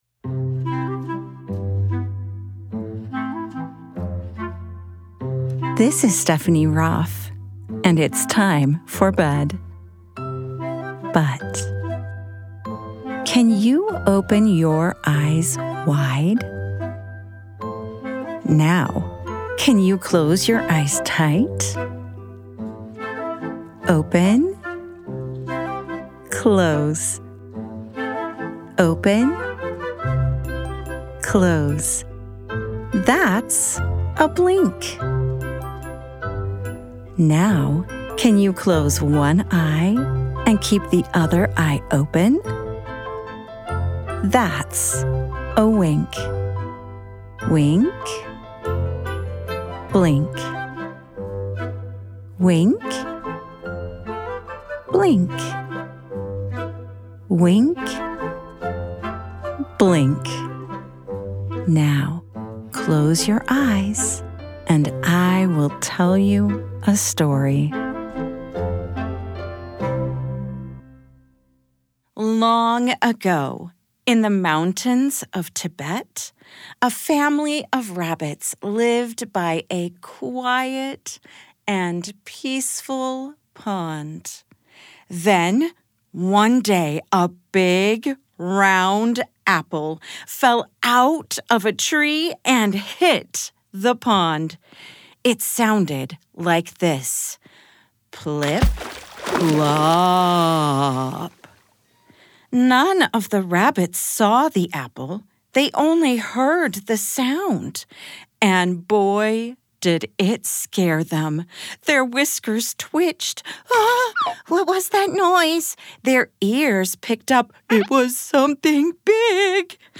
Plip Plop: A Mindful Bedtime Story For Kids